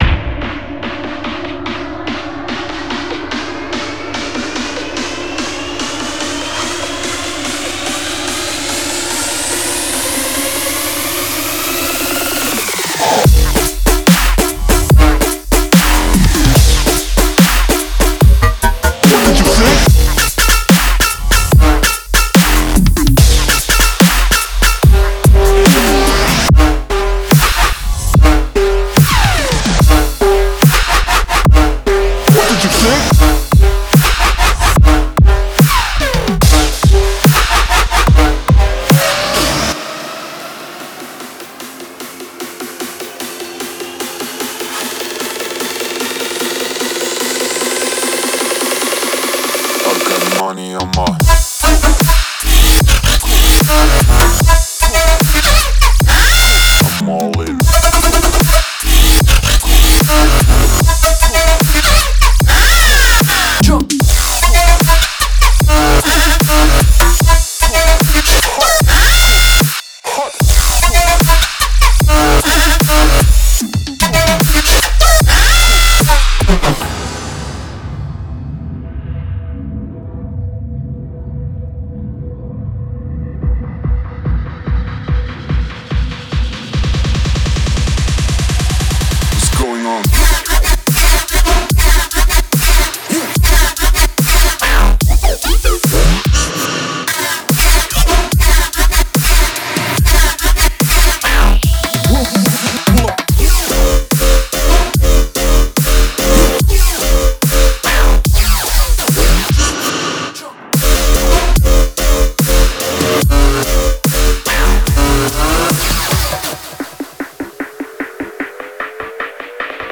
DubstepRiddimDubstep
我们还使用了优质的鼓机（NI Maschine，TR909）和舷外齿轮来产生最重击，坚韧不拔，重量级的声音！
• 鼓和打击乐循环（101）